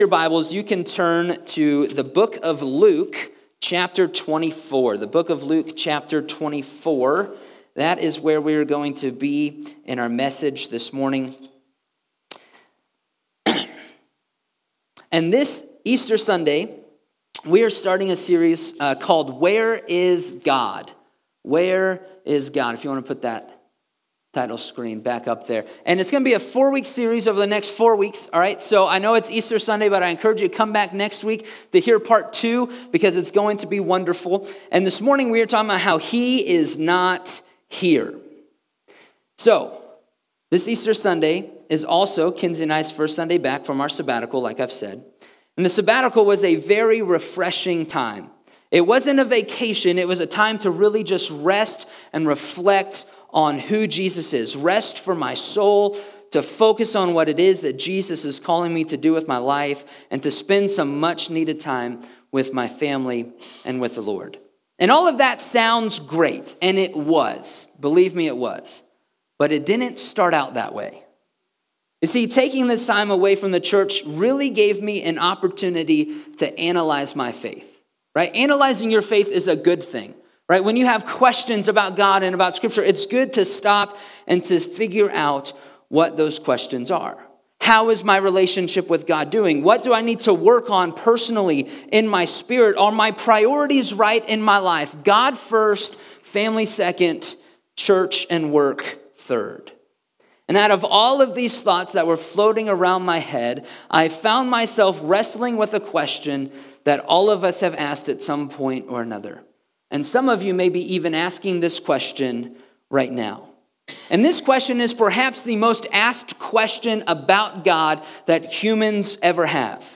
Sermons | Mountain View Assembly